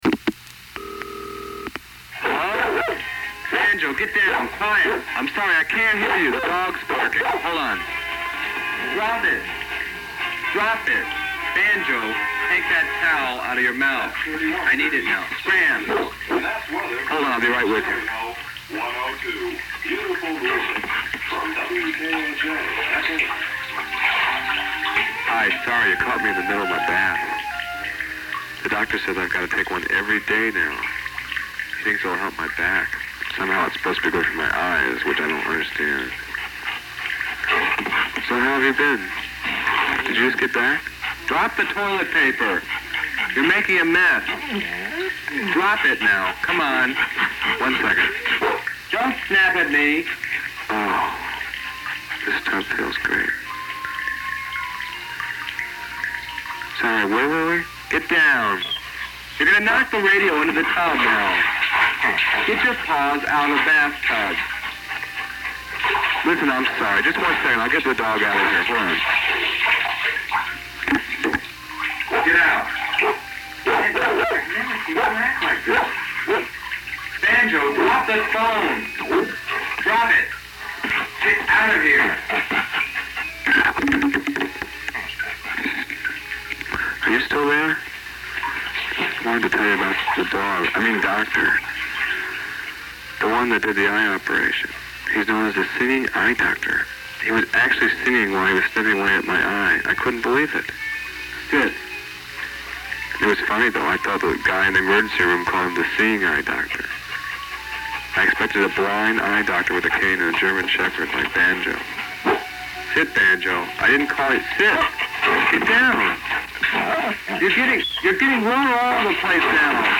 The two Telephone Stories below are excerpted from a 1980 National Public Radio interview in connection with the exhibition.